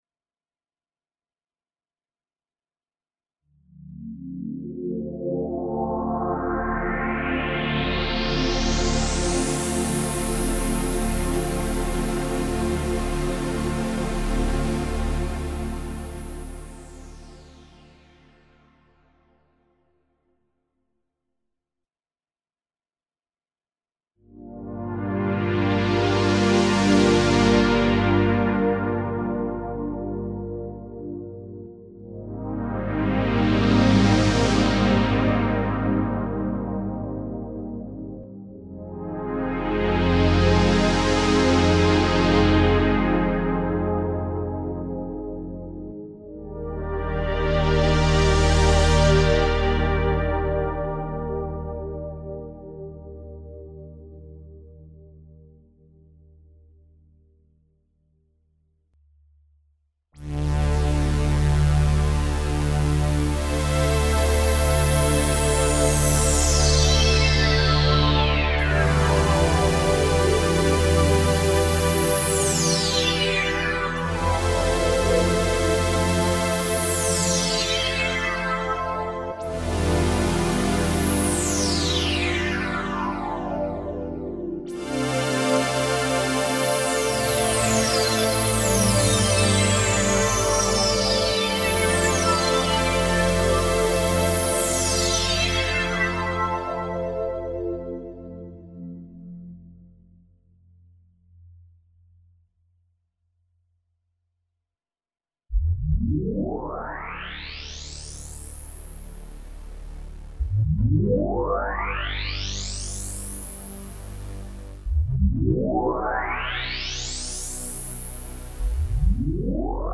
sweep-demo-radias.mp3